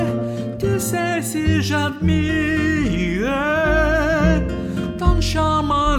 Добавляем всего одну дольку - и вальсирующая серенада из Пертской красавицы превращается в знойное танго.